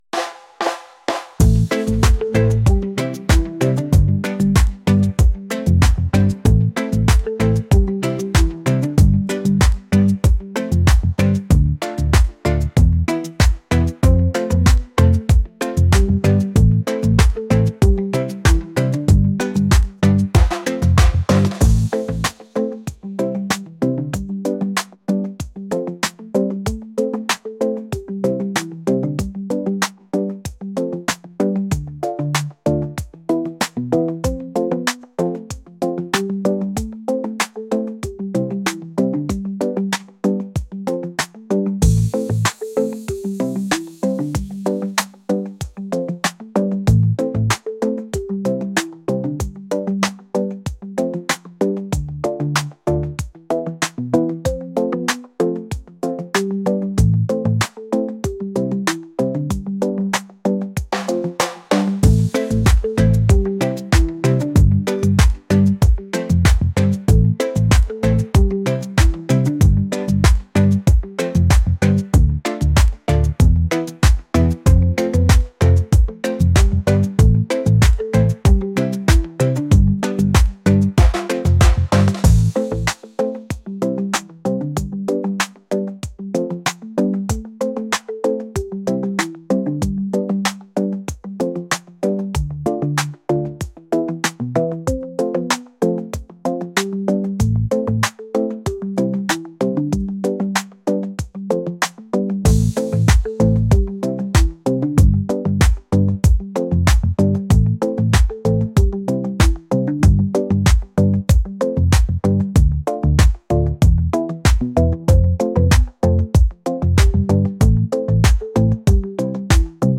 reggae | lofi & chill beats | ambient